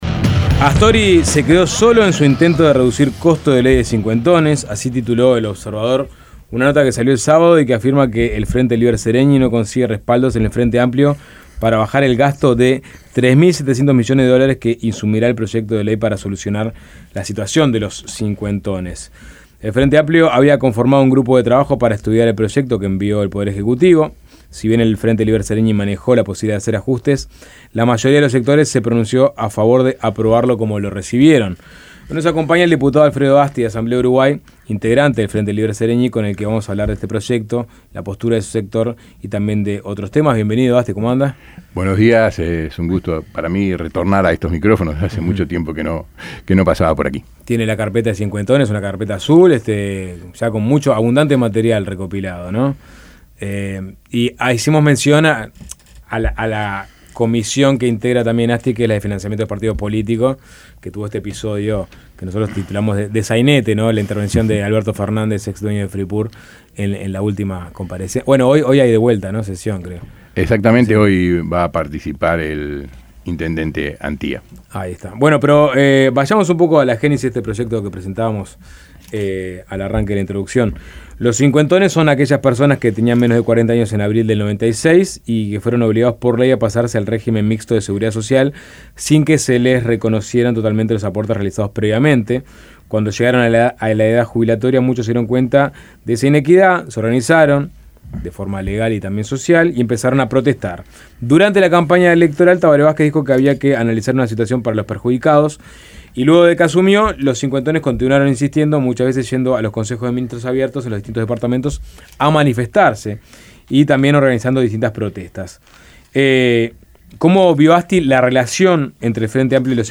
El diputado del Frente Amplio por Asamblea Uruguay, Alfredo Asti, estuvo en Suena Tremendo para hablar de los cincuentones, la supuesta suba de tarifas de UTE y la reforma del Banco República.